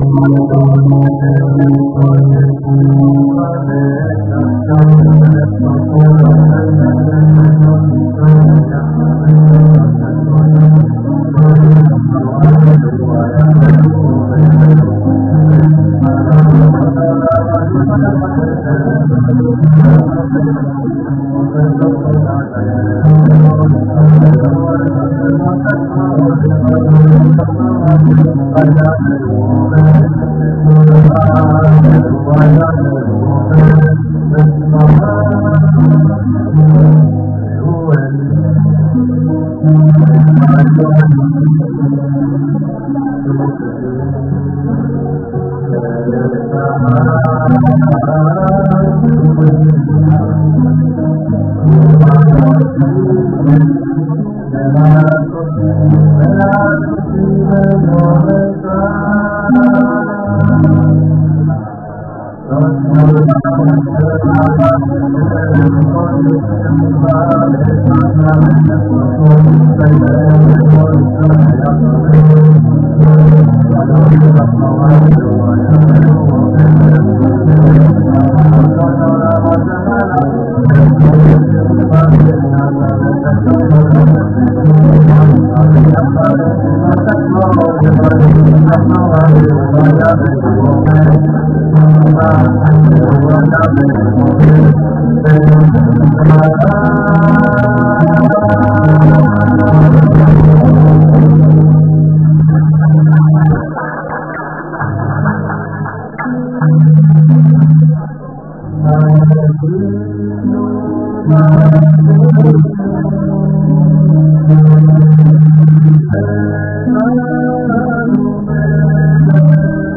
שירים בערב של העמותה י אלול
שירים-בערב-של-העמותה-י-אלול.m4a